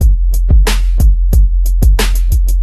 That Ass Drum Loop.wav